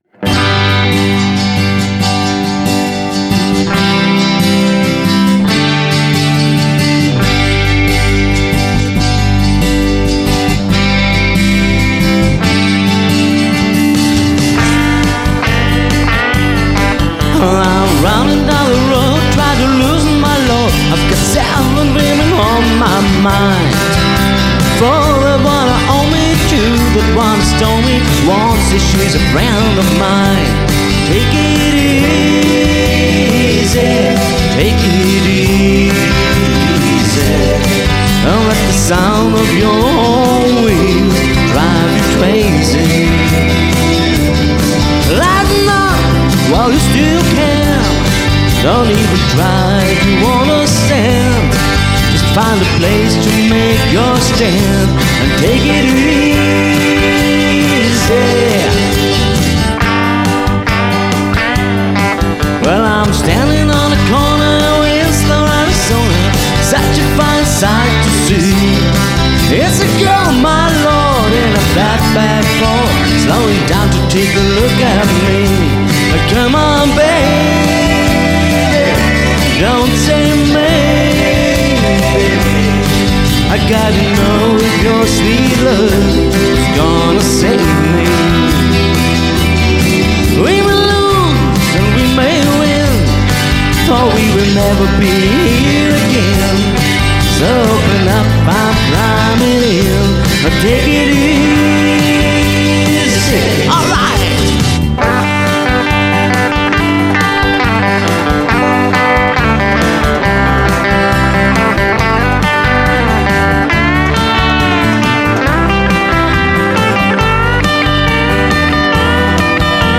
Coverversionen mit E-Gitarre, Gesang und Backing Track (BT).